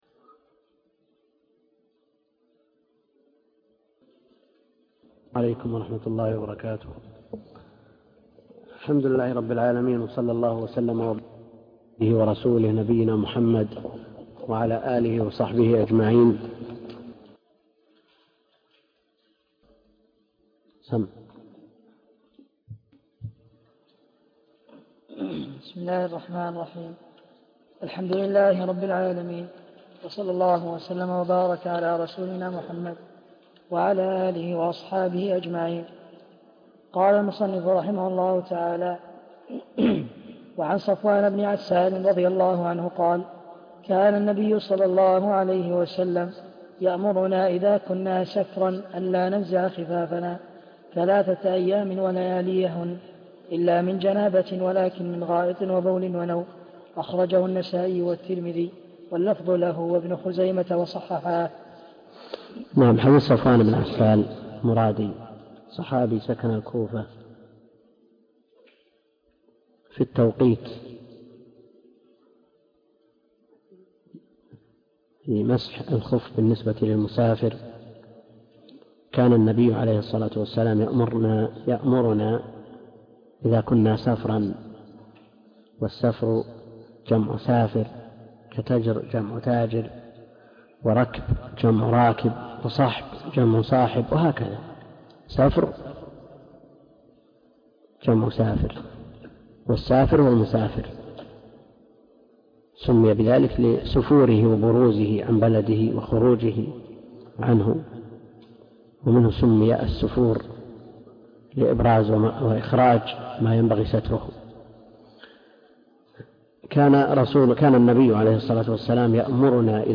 عنوان المادة الدرس (10) كتاب الطهارة من بلوغ المرام تاريخ التحميل الأحد 28 يناير 2024 مـ حجم المادة 16.13 ميجا بايت عدد الزيارات 226 زيارة عدد مرات الحفظ 105 مرة إستماع المادة حفظ المادة اضف تعليقك أرسل لصديق